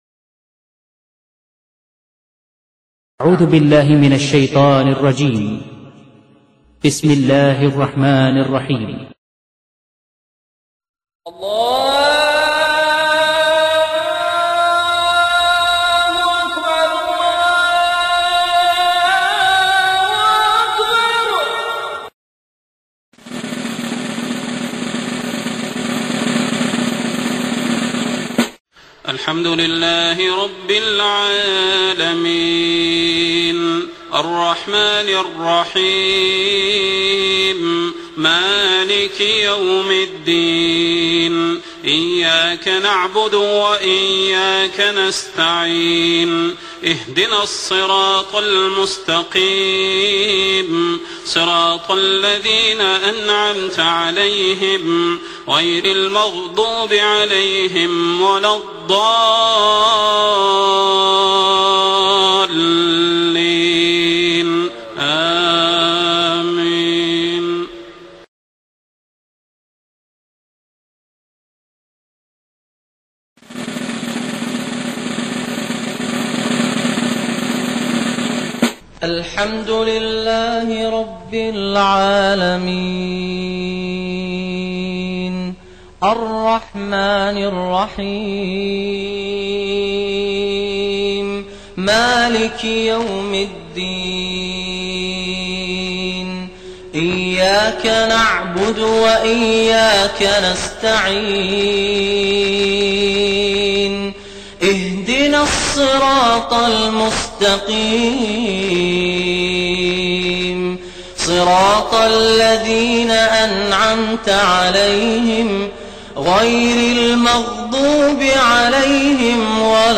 Surah Al-Fatihah – 5 Reciters
My Favorite Reciters: